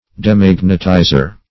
demagnetizer - definition of demagnetizer - synonyms, pronunciation, spelling from Free Dictionary
De*mag"net*i`zer, n.